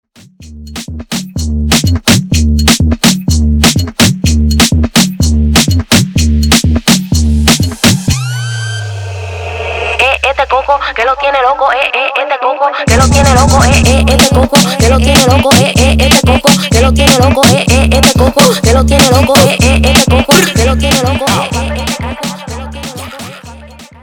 Extended Dirty Intro